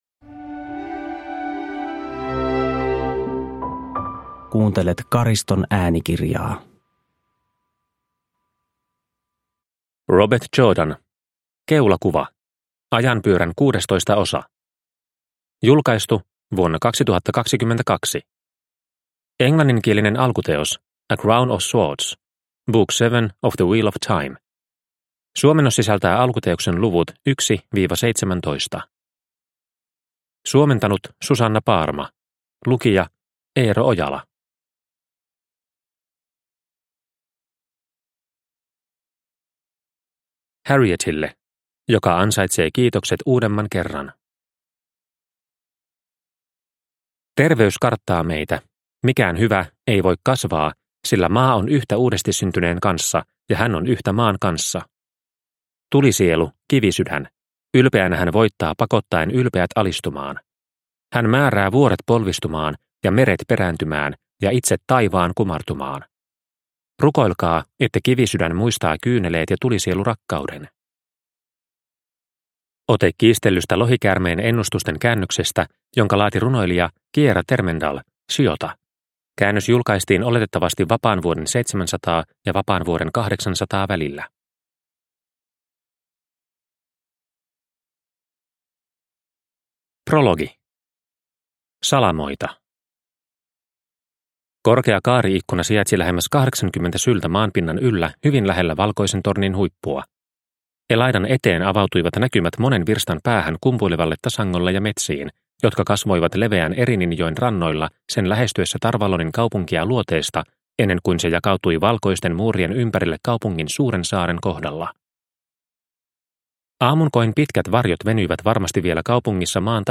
Keulakuva – Ljudbok – Laddas ner